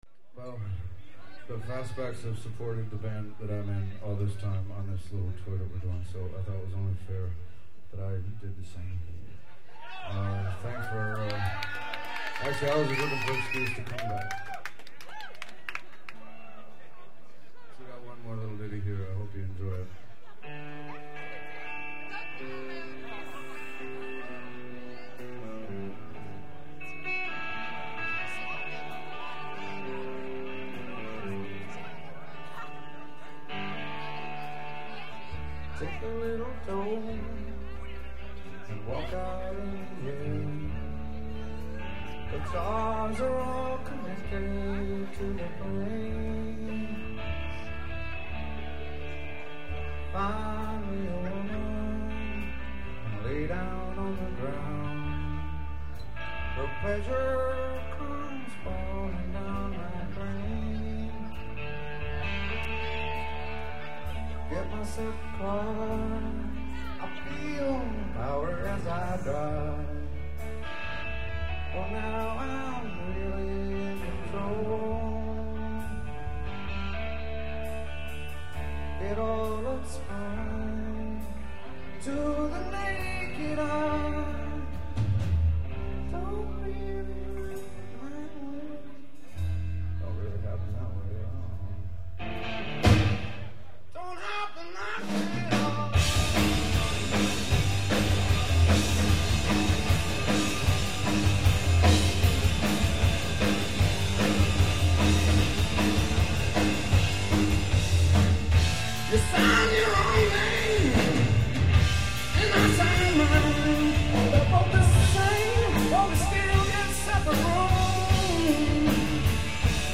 as performed live
at the Vera Club in Holland